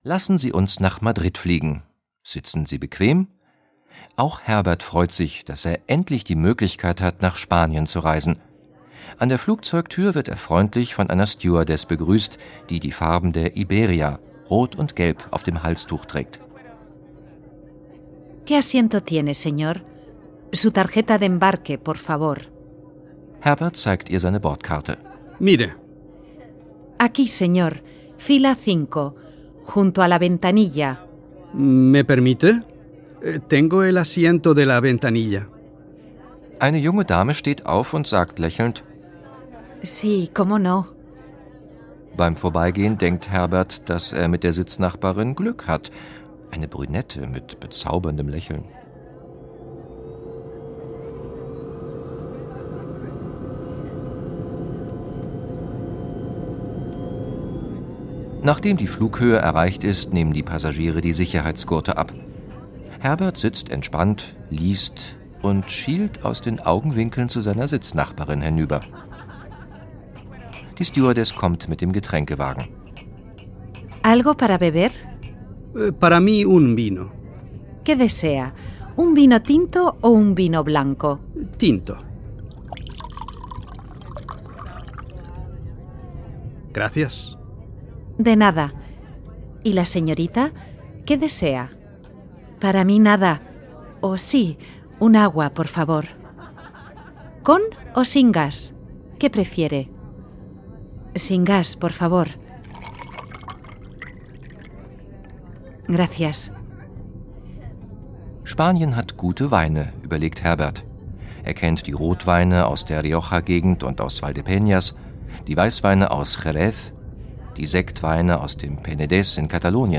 Der Sprachkurs zum Hören mit 4 Audio-CDs und Begleitheft
Dialoge mit Übersetzung